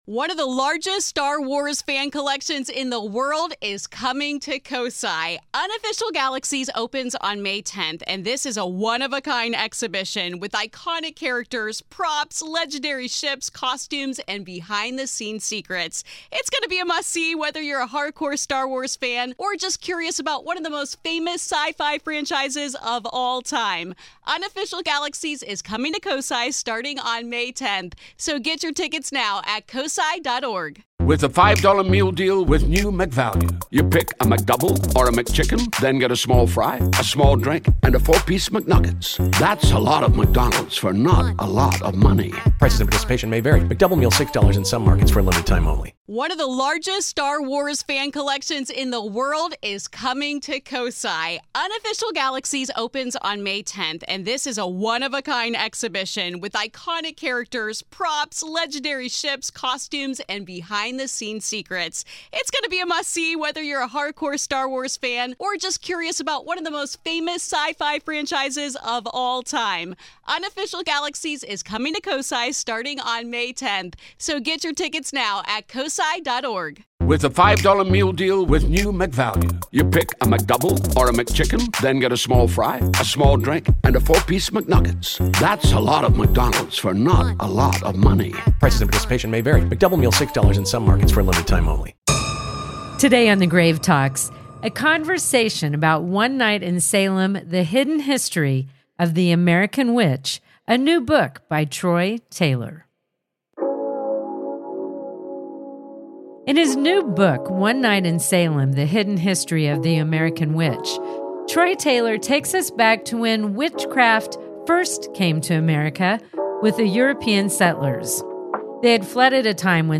In this episode, we look into the hidden history of the American witch and how it continues today. This is Part Two of our conversation.